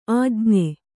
♪ ājñe